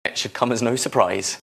This means that Sir sounds like the weak first syllable of surprise: